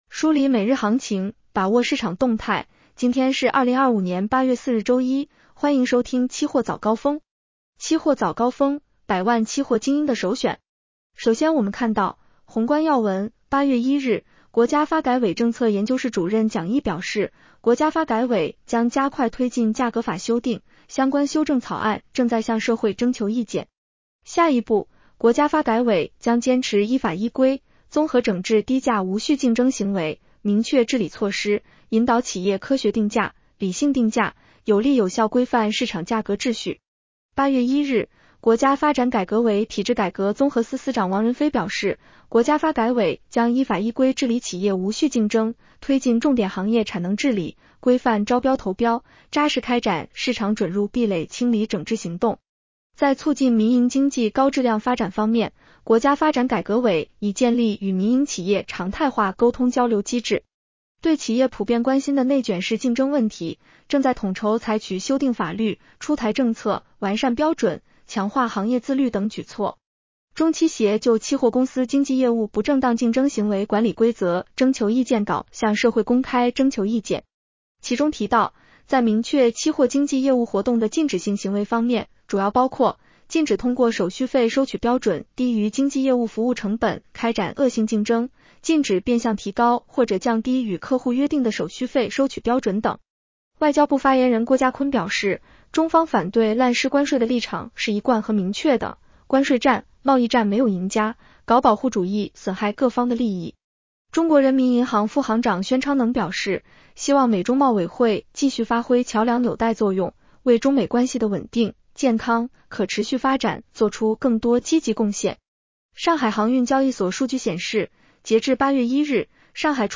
期货早高峰-音频版 女声普通话版 下载mp3 宏观要闻 1. 8月1日，国家发改委政策研究室主任蒋毅表示， 国家发改委将加快推进价格法修订，相关修正草案正在向社会征求意见 。